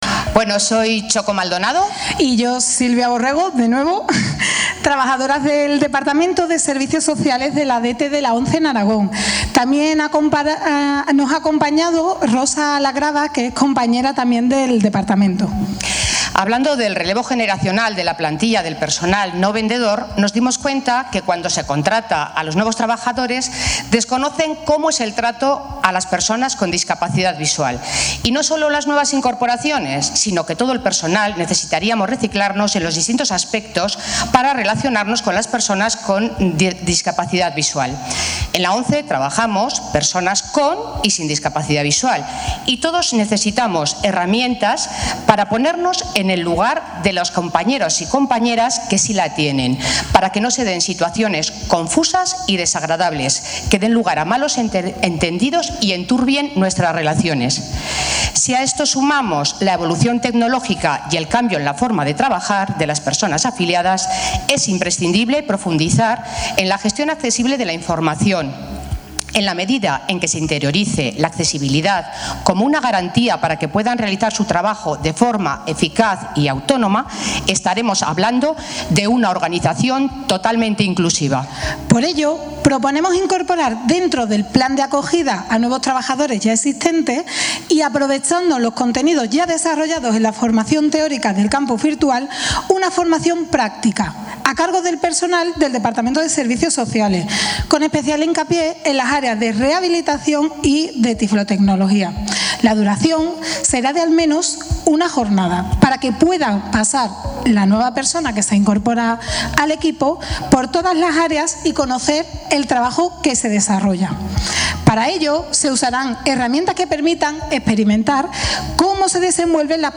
en la gala.